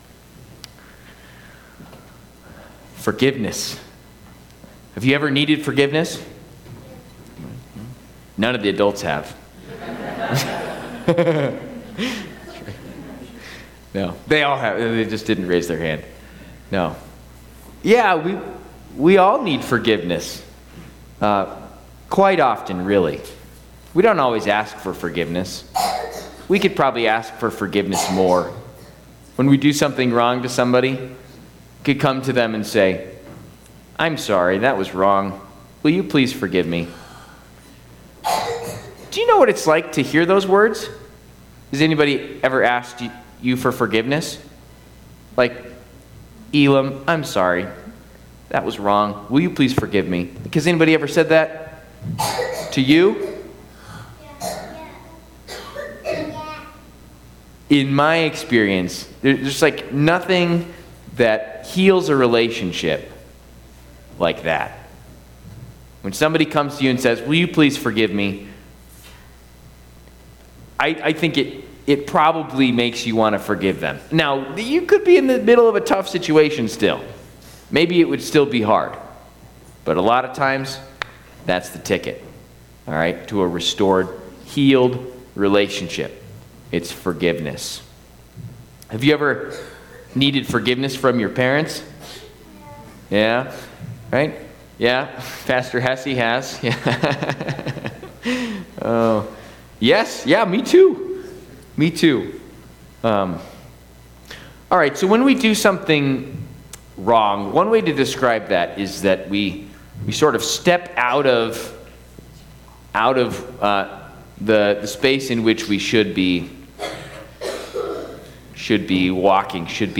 Fourth Week of Lent Midweek service with school chapel - working through a study of the Lord's Prayer